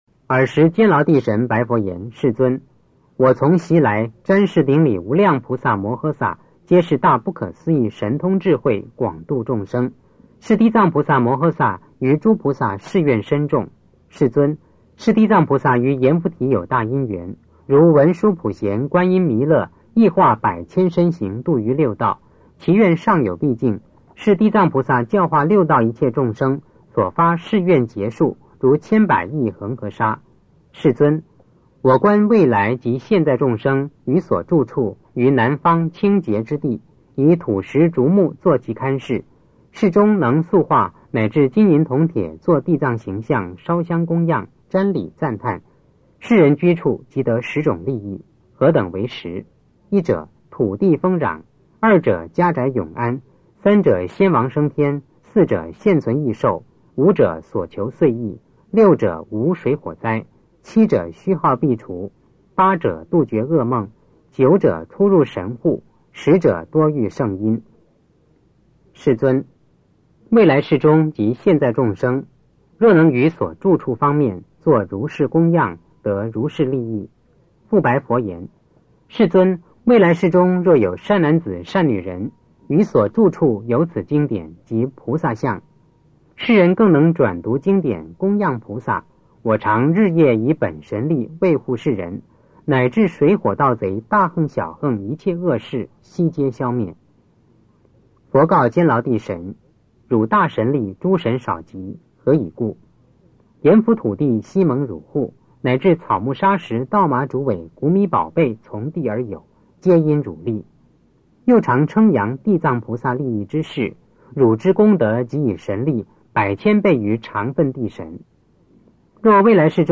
地藏经(男声念诵）8